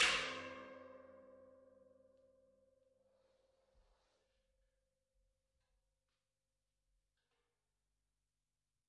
描述：大金属罐，用鼓棒的各种敲击声用一个EV RE20和两个压缩的omni麦克风录制的立体声大量的房间声音
Tag: 大 - 锡 工业 金属 金属